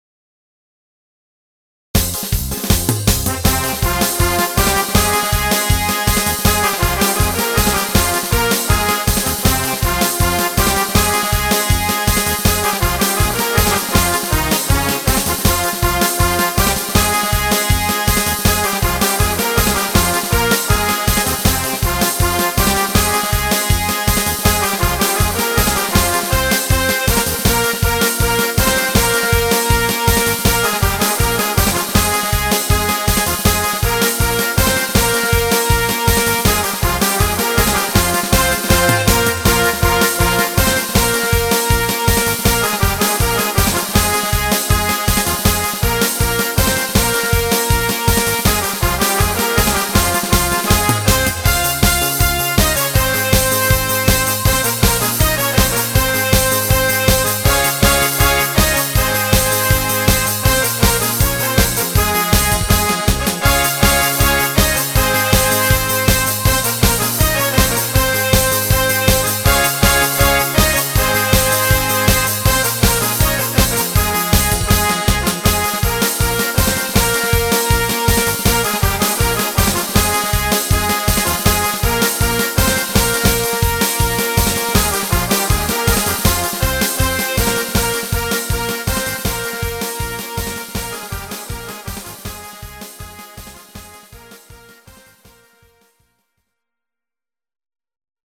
(באמצע עברתי למקצב של 3/4 לפי השיר).